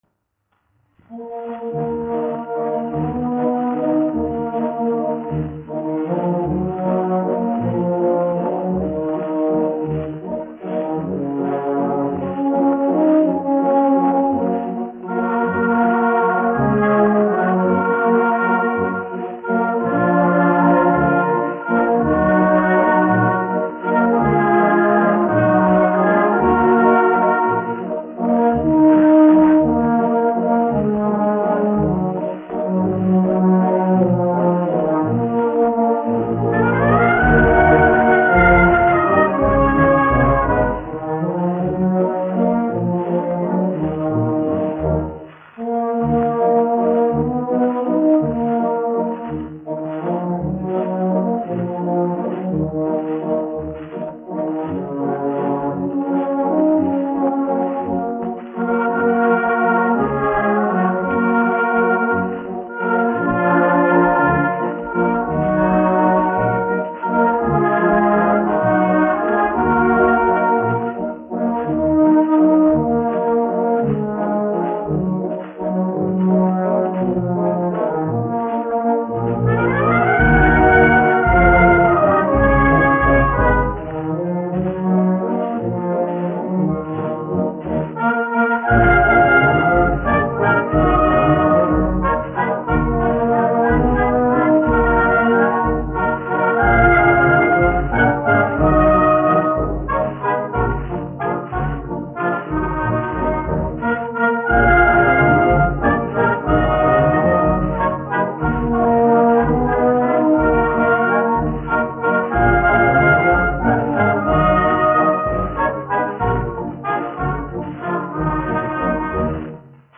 1 skpl. : analogs, 78 apgr/min, mono ; 25 cm
Pūtēju orķestra mūzika
Latvijas vēsturiskie šellaka skaņuplašu ieraksti (Kolekcija)